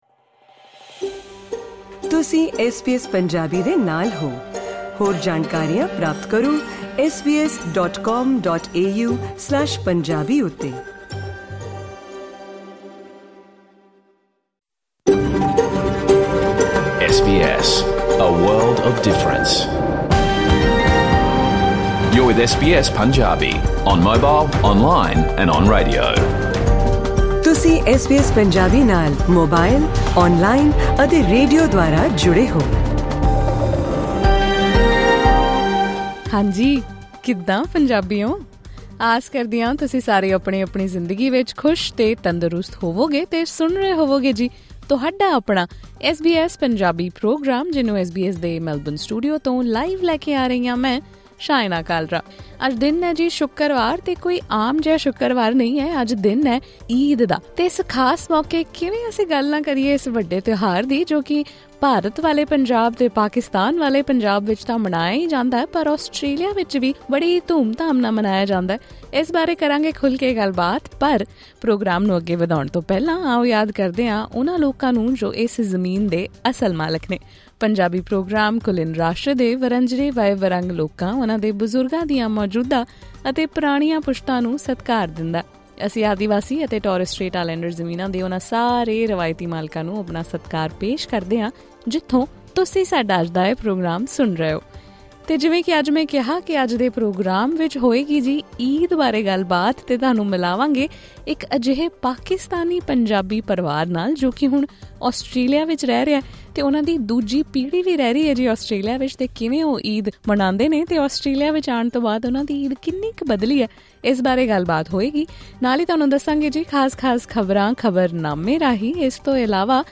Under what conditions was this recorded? In this SBS Punjabi Eid special radio program, listen to the story of a Pakistani Punjabi family and how their ways of celebrating Eid have changed after migrating to Australia.